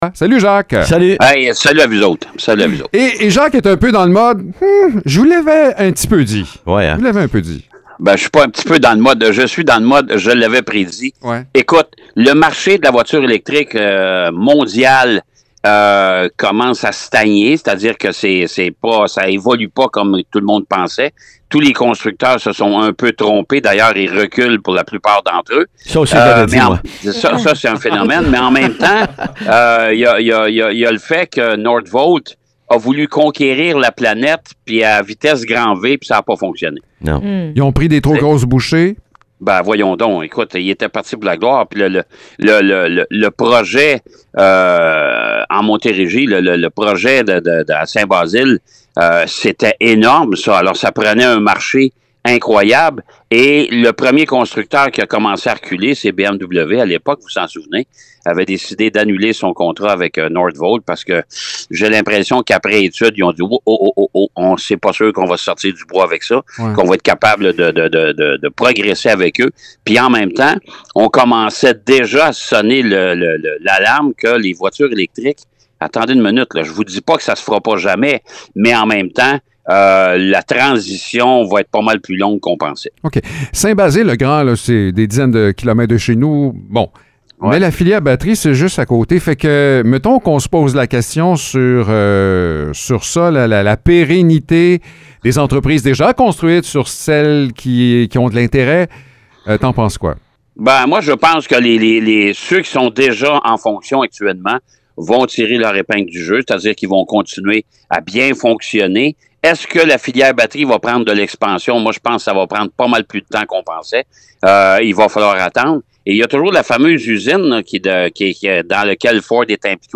Le chroniqueur automobile